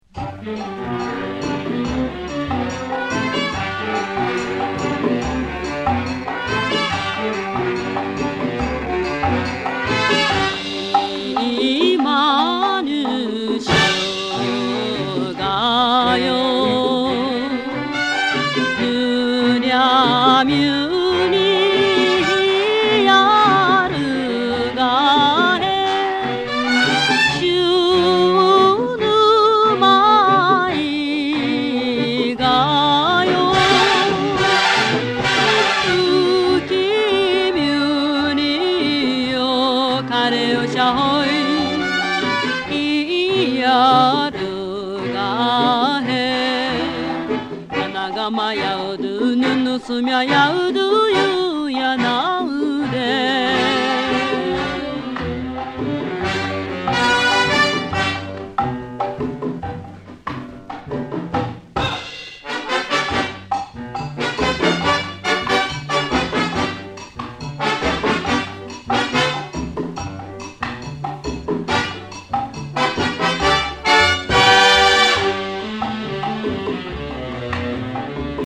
沖縄伝統音楽にロックやソウルのグルーヴを施した人気コンピレーション